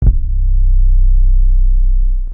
808s
GangstaSub_YC.wav